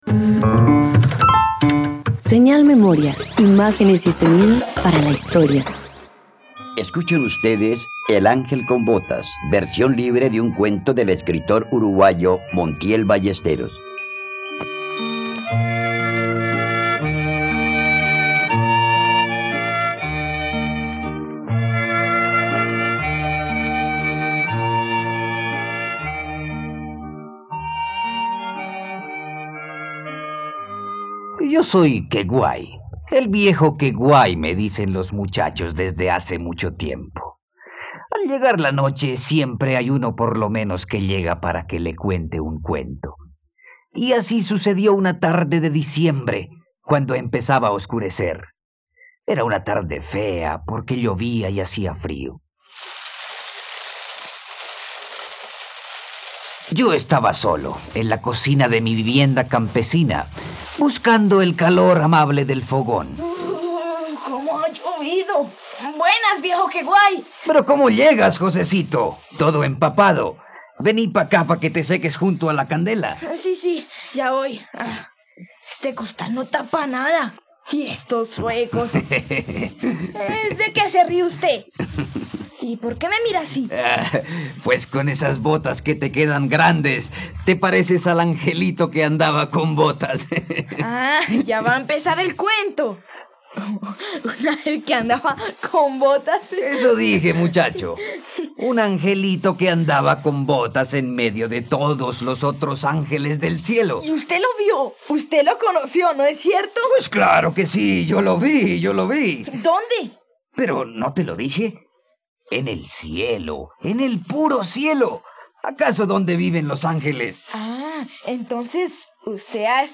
El ángel con botas - Radioteatro dominical | RTVCPlay